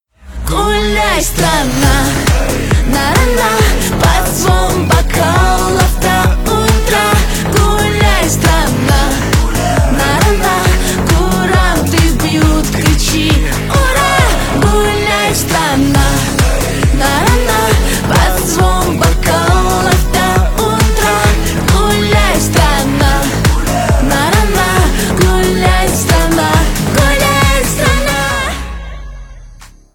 ремиксы
поп